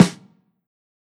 Snares
SNARE_SNEAK_ATTACK.wav